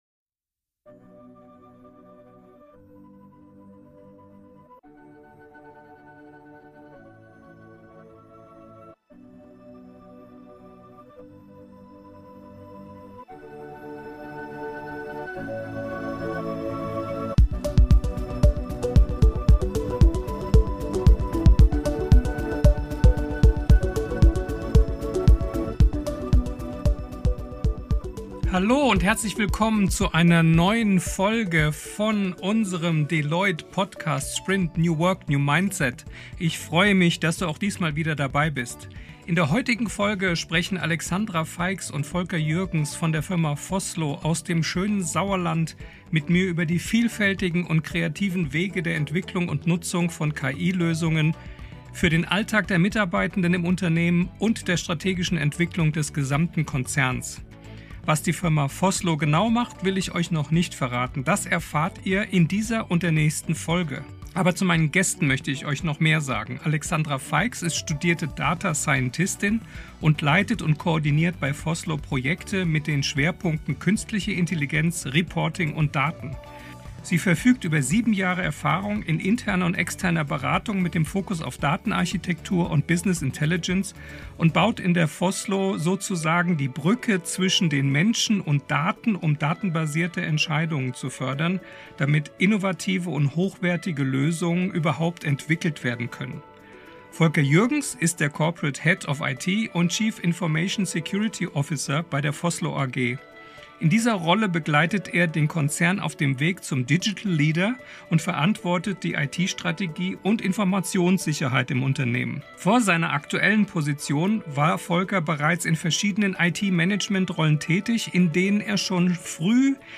Play Rate Listened List Bookmark Get this podcast via API From The Podcast In Sprint: New Work – New Mindset diskutieren Vordenker:innen, Entscheider:innen und auch Wissenschaftler:innen wie ein veränderungsfähiges und innovatives Unternehmen aufgebaut werden kann.